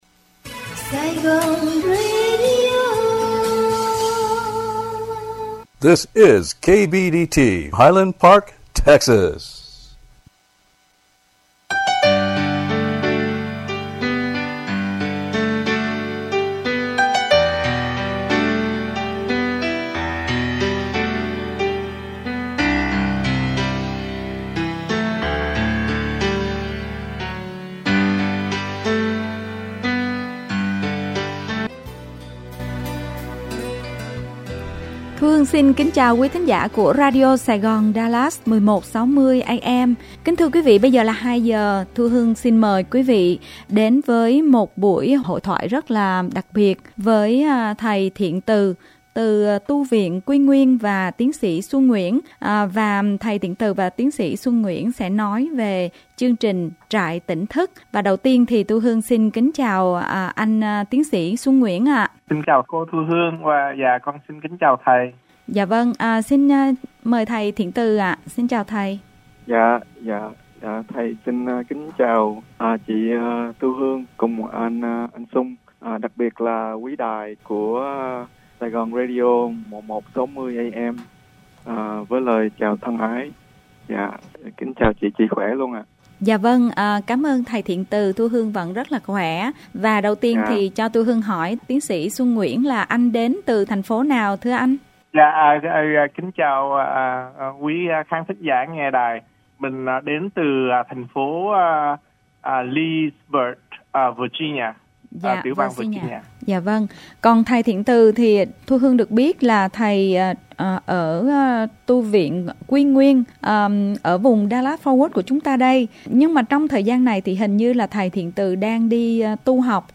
Talk show về trại Tỉnh Thức Tháng 8 2023 tại Dallas